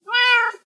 sounds / mob / cat / meow2.ogg
meow2.ogg